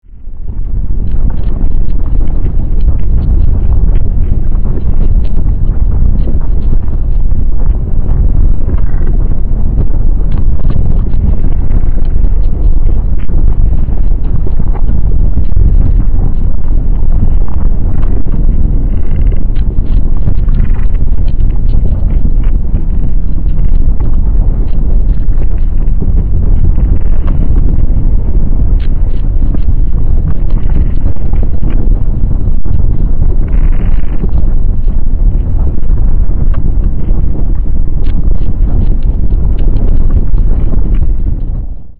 lava_rumble.wav